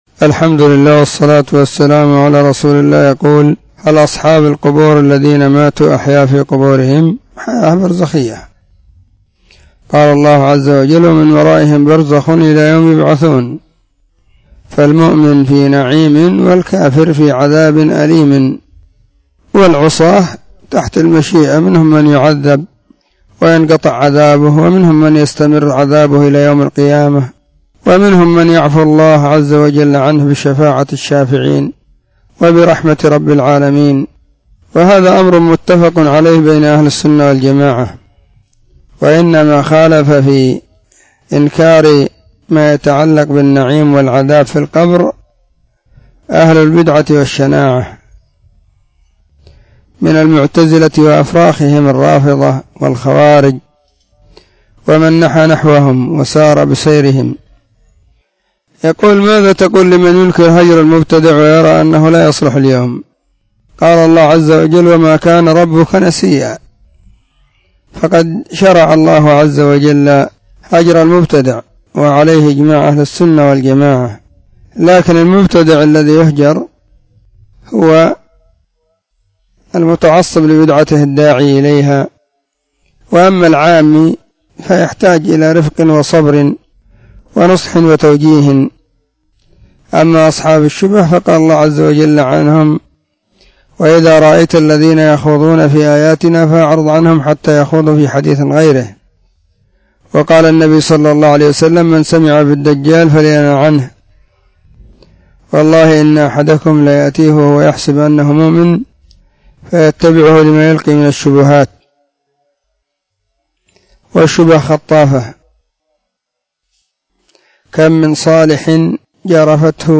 الأحد 23 ربيع الثاني 1443 هــــ | فتاوى مجموعة | شارك بتعليقك